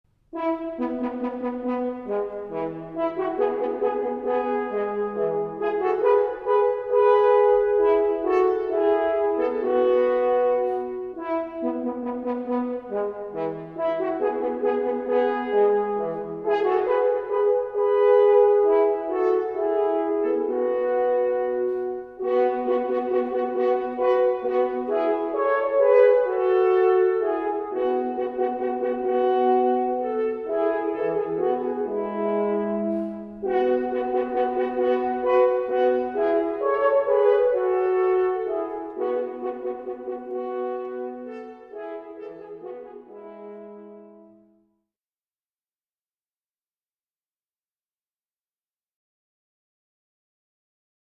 Horn
Blechblasinstrumente
• Das Horn (oder Waldhorn) ist das Blechblasinstrument, das von tief bis hoch fast alles spielen kann.
28-Horn.mp3